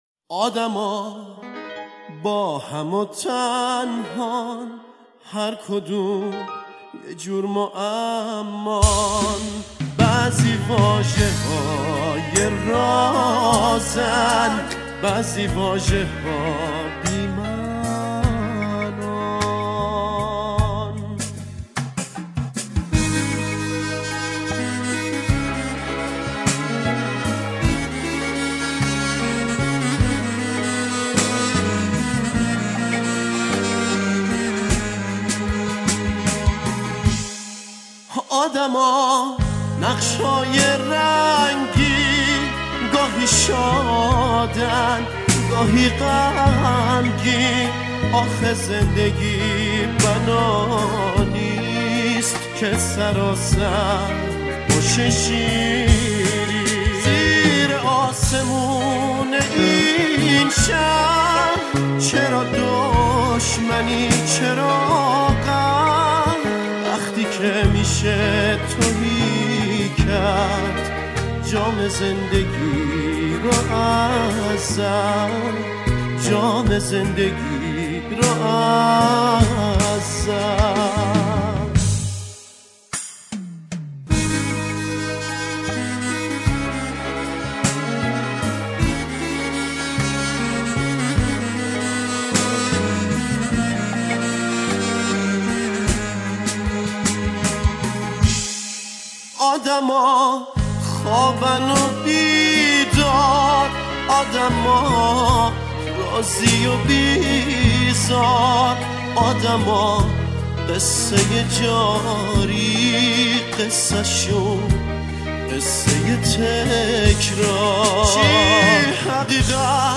موسیقی پاپ
خواننده سبک پاپ است